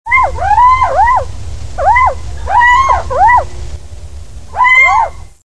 La chouette chevêche, Athene noctua.
Son chant d'amour, véritable appel à sa compagne, que j'entends depuis chez moi, n'est-il pas le gage d'une certaine survivance potentielle de cette espèce?
cheveche.mp3